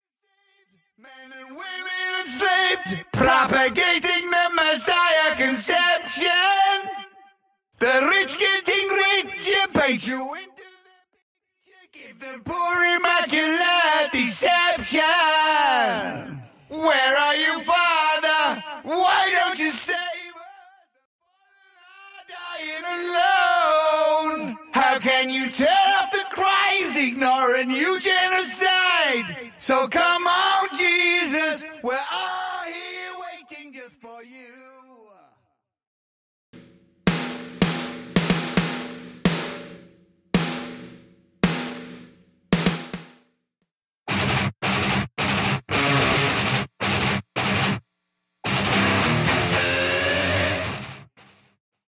Studio Backing Vocals, Synths & Backing Strings Stem
Studio Bassline Guitar Stem
Studio Cymbals Stem
Studio Guitar Stem
Studio Kick Stem
Studio Snares Stem